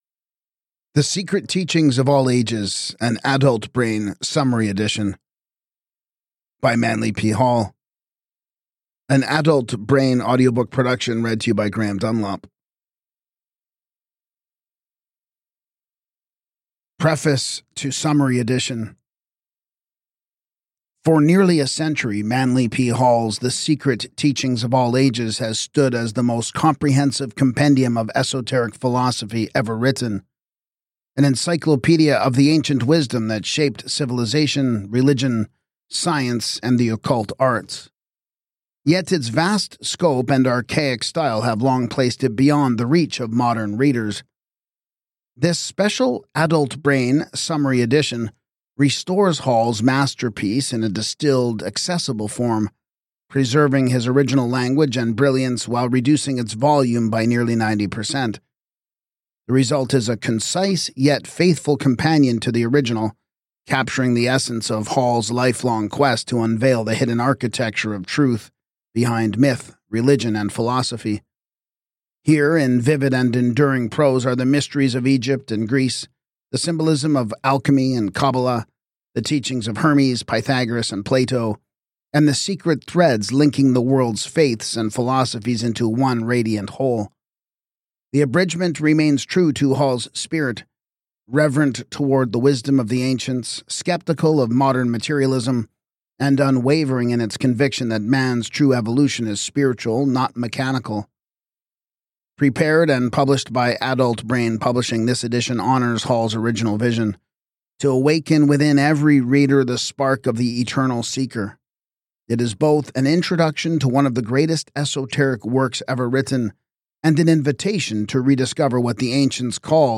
Podcast (audiobooks)